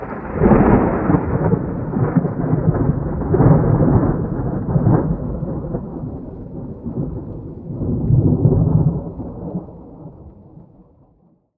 Thunder 1.wav